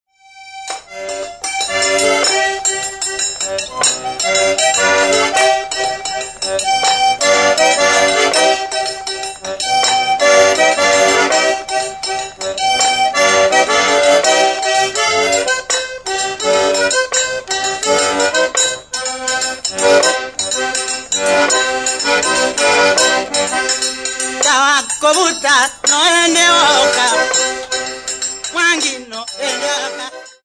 Folk songs, Kikuyu
Field recordings
sound recording-musical
Song to accompany a town dance in which men and women dance together in pairs, after the fashion of Europeans. The accordion is used as a ground which creates a happy noise but has little, if any, melodic or harmonic relationship to the tonality or mode of the voice. Topical song, with accordian and struck iron (-12.16-).